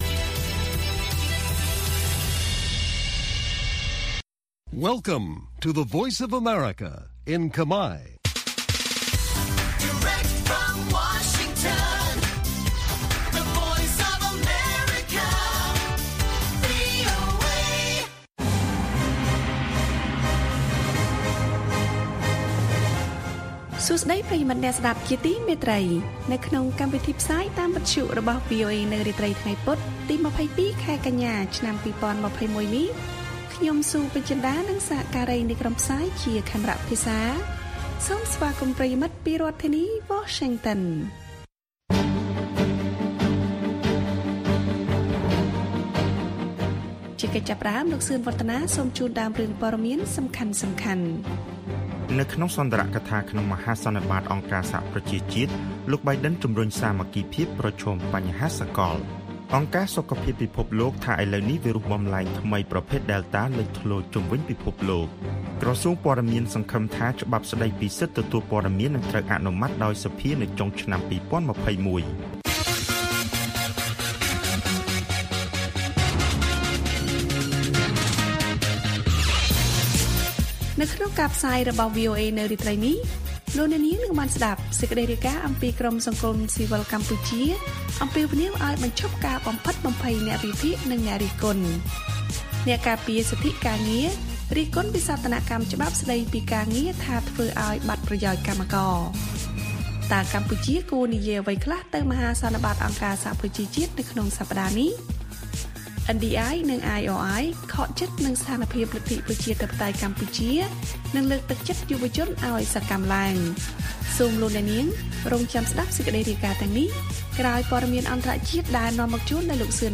ព័ត៌មានពេលរាត្រី៖ ២២ កញ្ញា ២០២១